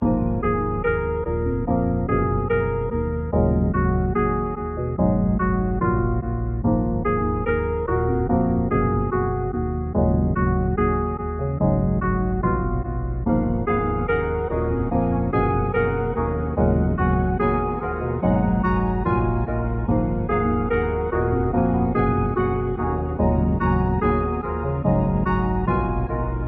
偏移型145bpm
Tag: 145 bpm Trap Loops Piano Loops 2.23 MB wav Key : Unknown FL Studio